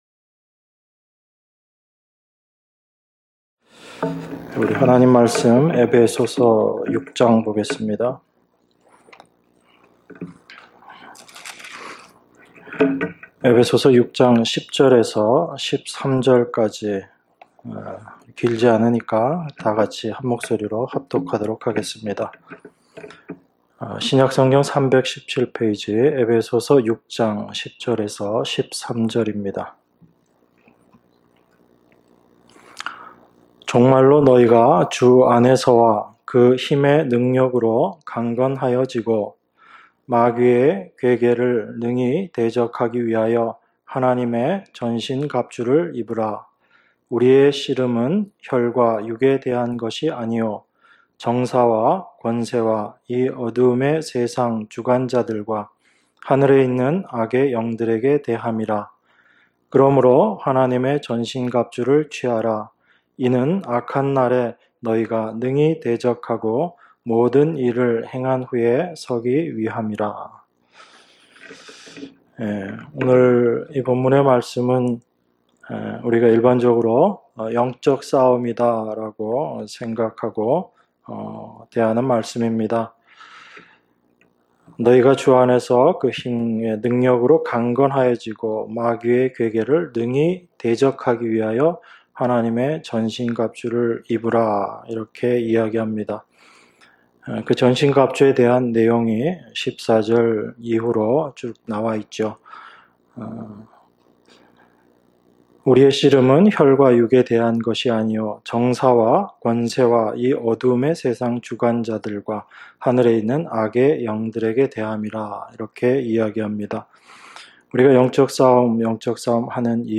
주일예배 - 에베소서 6장 10절 ~ 13절 주일 2부